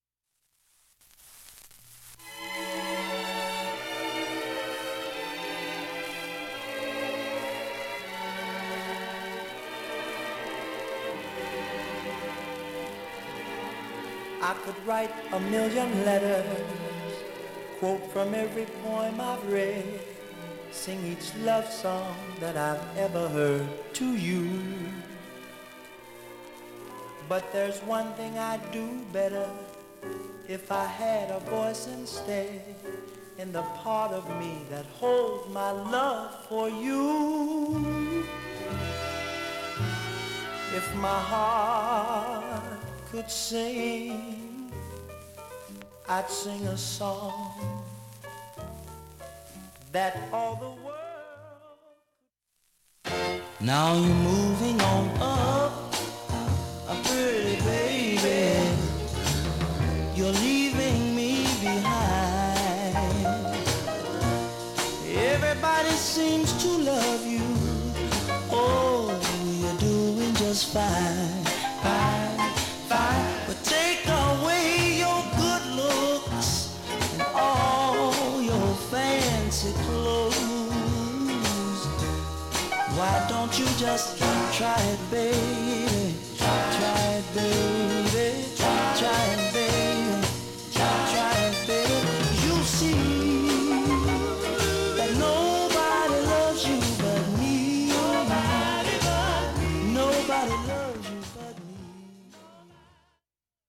サーフェスノイズ出ています試聴にてご確認ください。